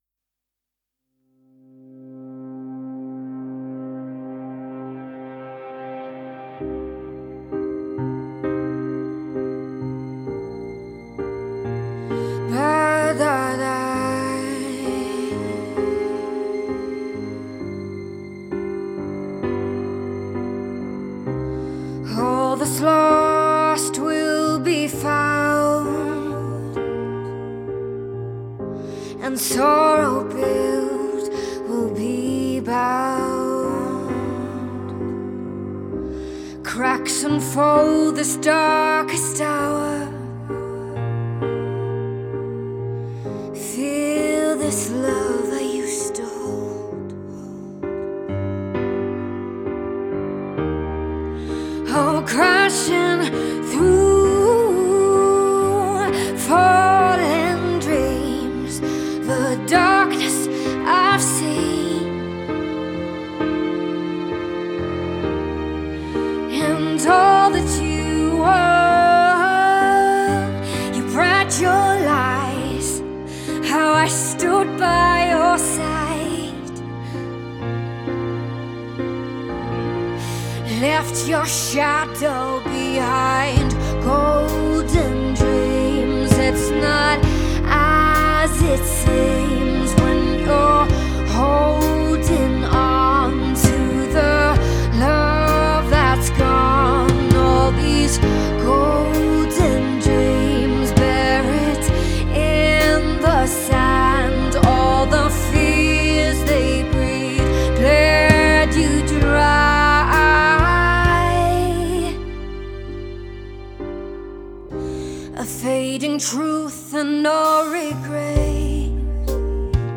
Slow Female